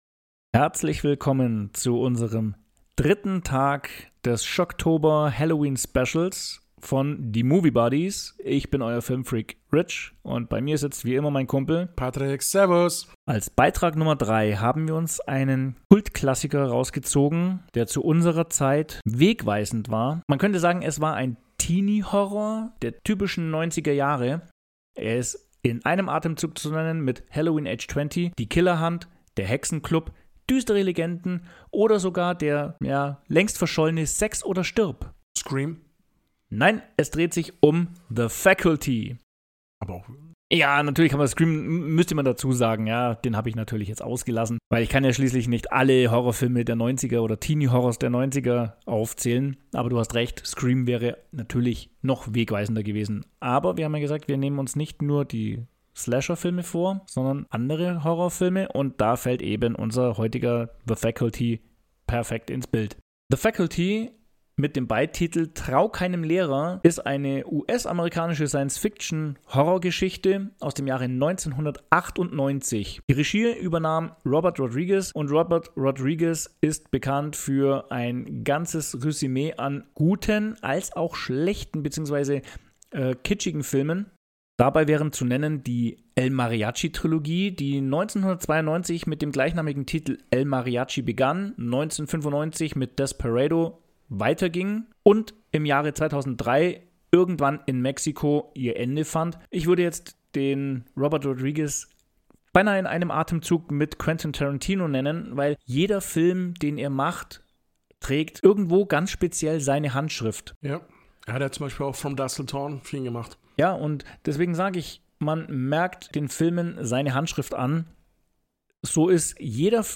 Erlebt eine spannende Diskussion über diesen Sci-Fi-Klassiker, welchen Einfluss er auf unsere Podcaster genommen hat und wie sie den Film wahrgenommen und erlebt haben.